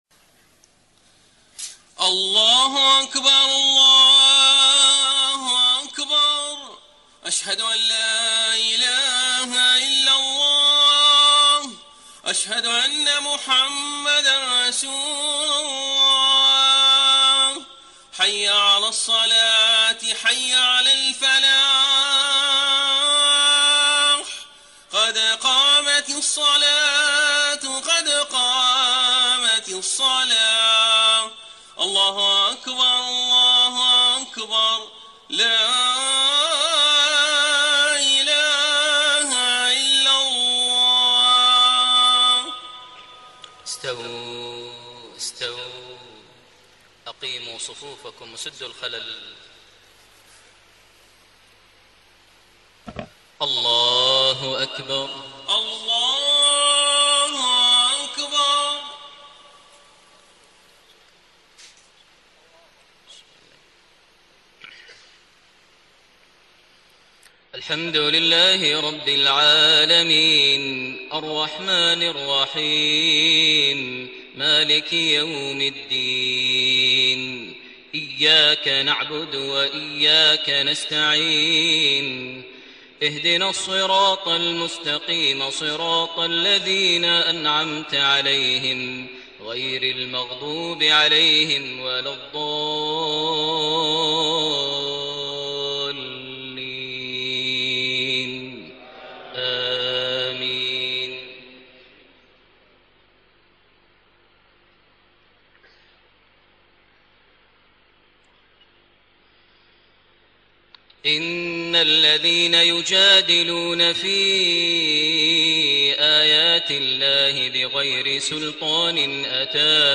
صلاة العشاء 6 شوال 1433هـ من سورة غافر 56-65 > 1433 هـ > الفروض - تلاوات ماهر المعيقلي